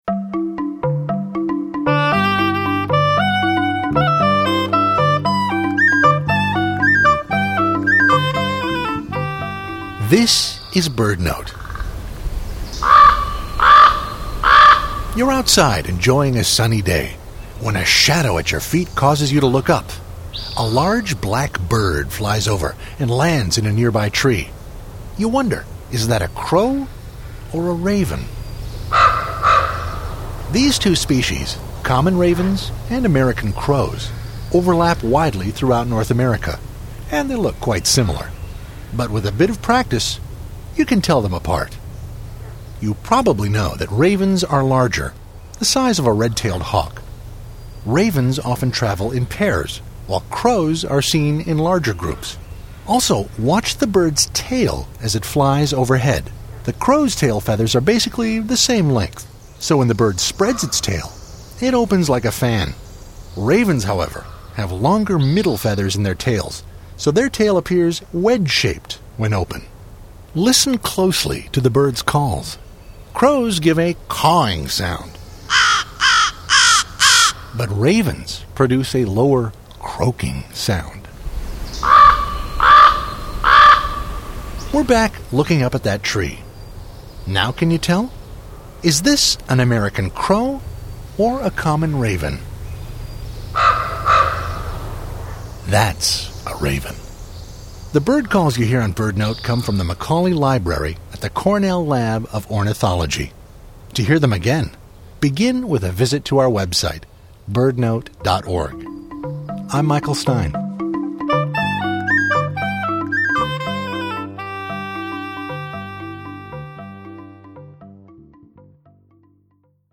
Is it a Crow or is it a Raven?
Sounds of both    ???